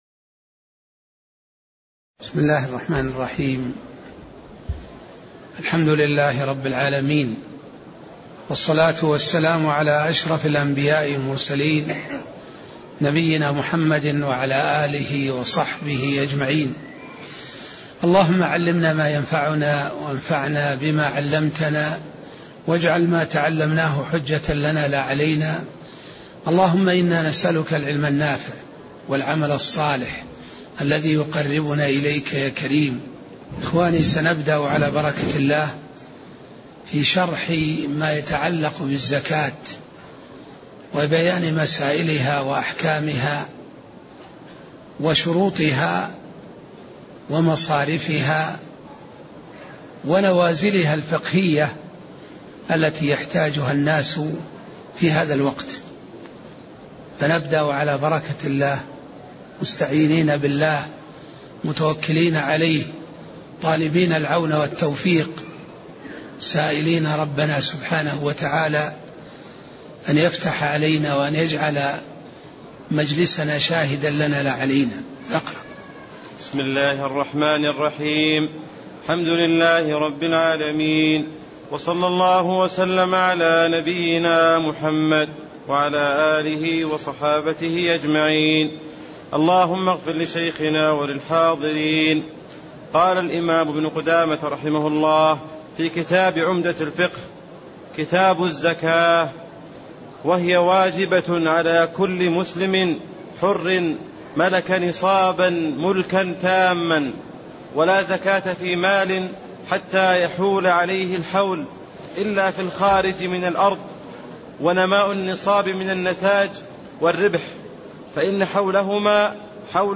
تاريخ النشر ٤ شعبان ١٤٣٤ هـ المكان: المسجد النبوي الشيخ